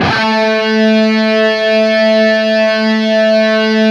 LEAD A 2 LP.wav